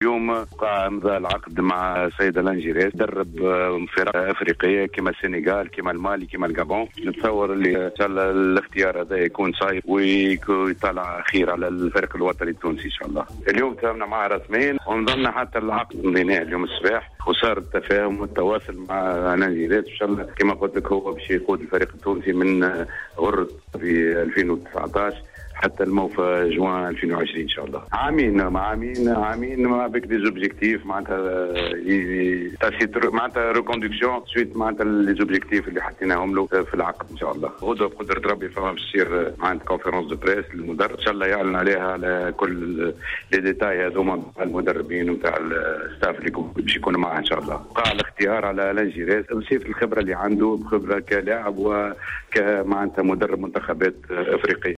مداخلة في حصة "Planète Sport"